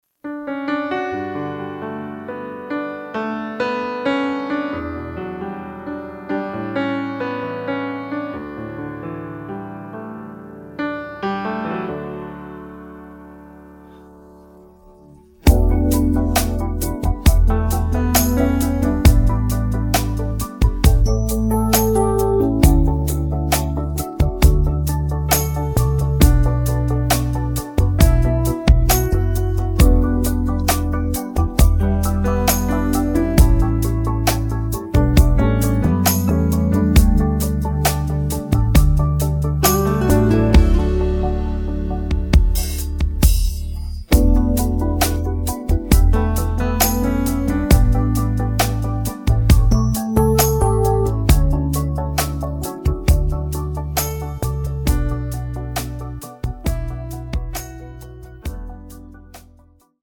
음정 원키 5:13
장르 가요 구분 Voice Cut